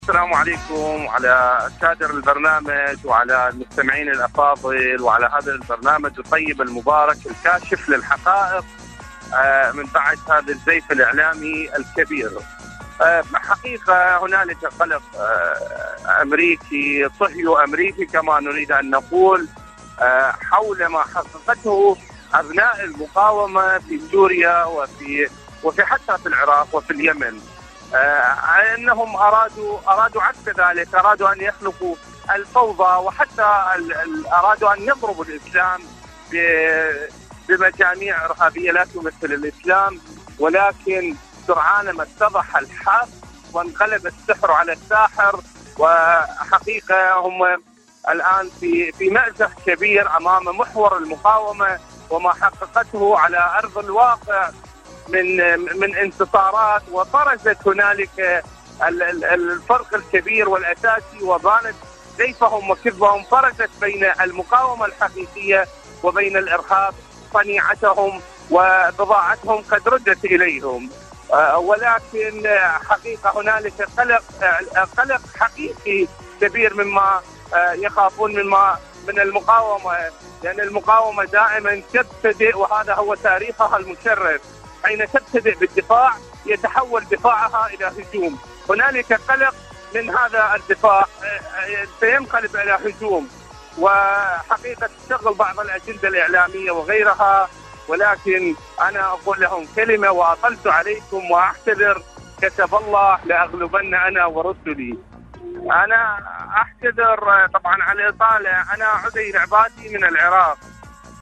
حدث وحوار / مشاركة هاتفية